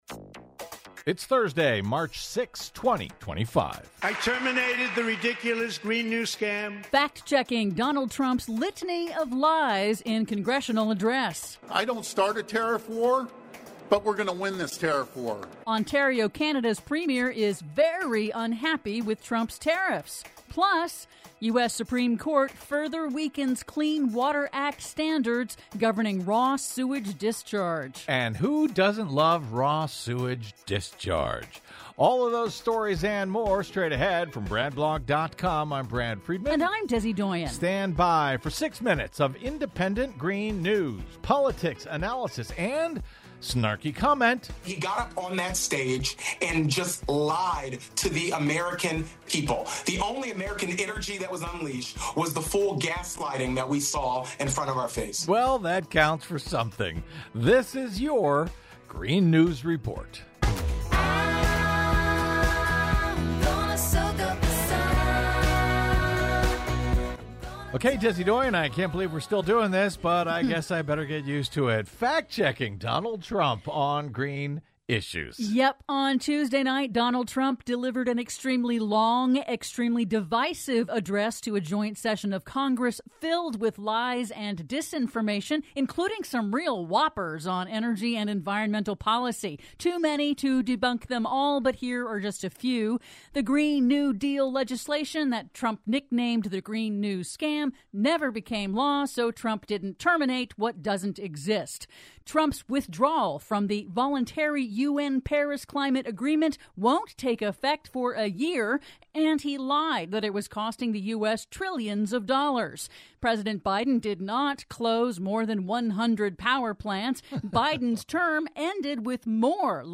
IN TODAY'S RADIO REPORT: Fact-checking Donald Trump's litany of lies in his address to a joint session of Congress; Premier of Ontario, Canada threatens to cut electricity to US over Trump tariffs; PLUS: U.S. Supreme Court further weakens Clean Water Act standards governing raw sewage discharge...